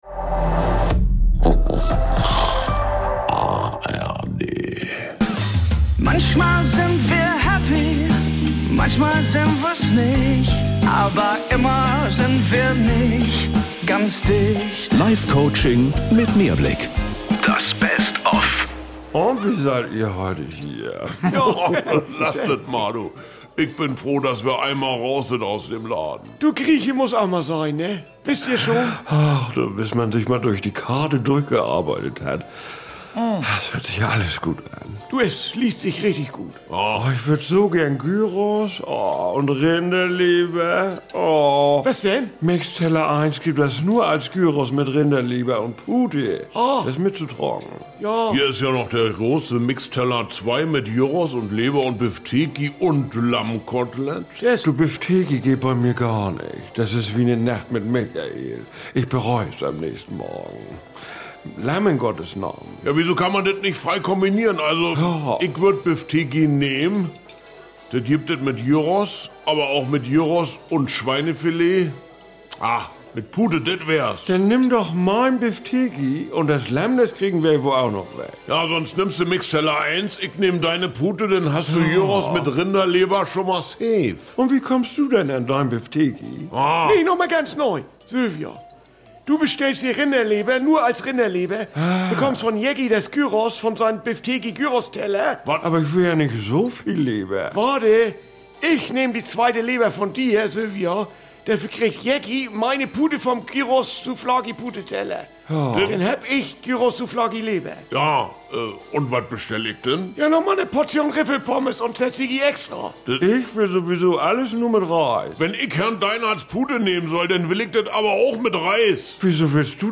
… continue reading 230 Episoden # Komödie # NDR